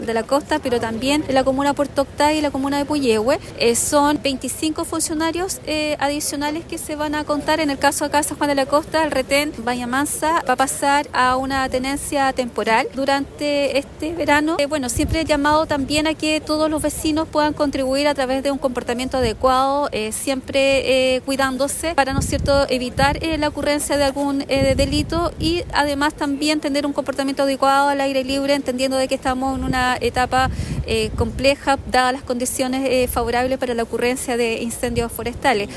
Por su parte, la delegada Presidencial Provincial, Claudia Pailalef, detalló la dotación extra en la zona.